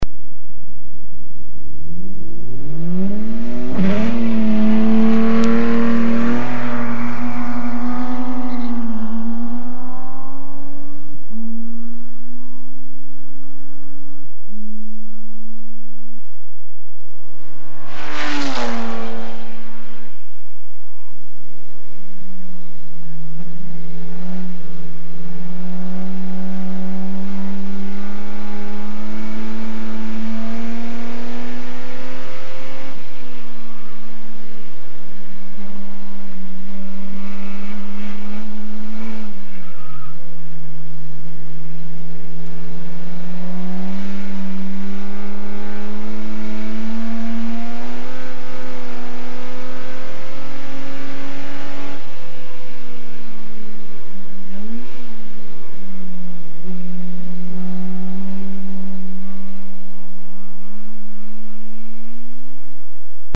A jármű hangja általában hármas felosztásban hallható:
indulás, elhaladás, kocsiban ülve.
Jaguar_D-Type.mp3